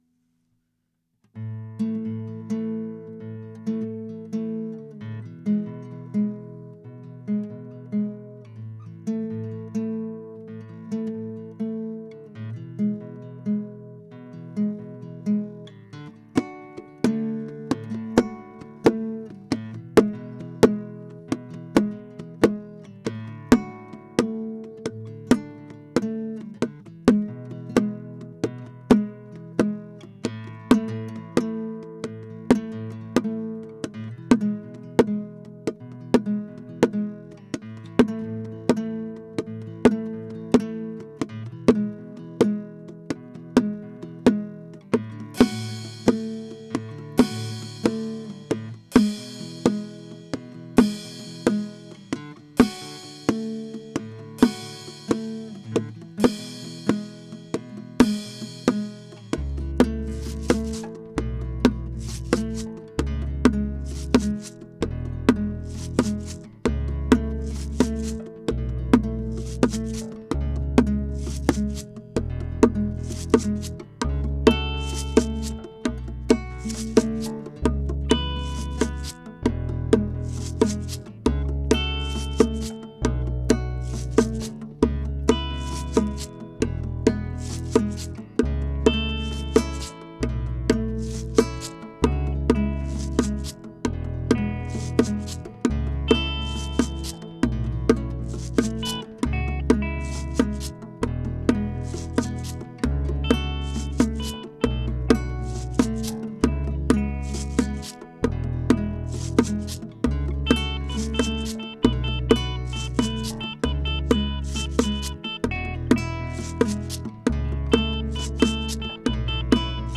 Voici une première reprise, version acoustique :